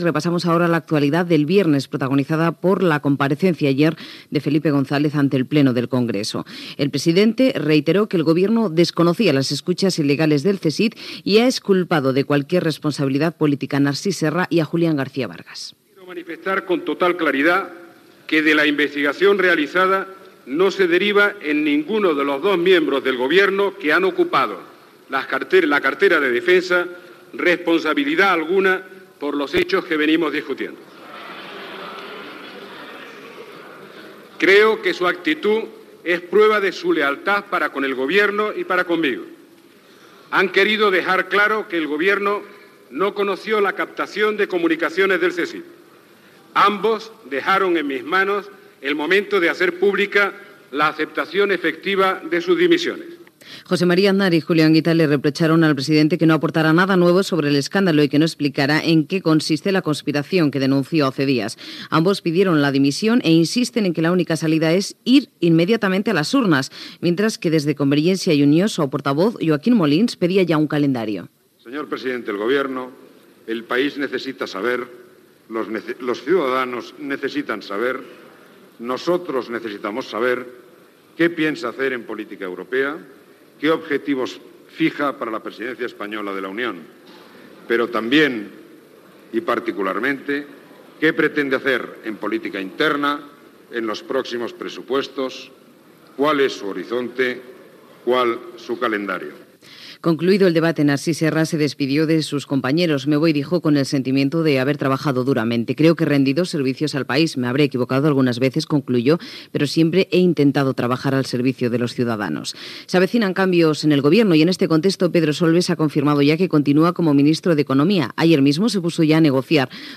Declaracions de Felipe González sobre les escoltes il·legals del Centro Superior de Información de la Defensa al debat del Congrés de Diputats, intervenció Joaquim Molins. Canvis al govern espanyol, vaga de benzineres, campanya de la renda, etc. Careta de sortida.
Informatiu